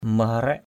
/mə-ha-rɛʔ/ (t.) thon, lưng ong = à la taille de guêpe, à la taille fine. ka-ing nai ni maharaik ki{U =n n} mh=rK eo cô ấy thon. cette fille a...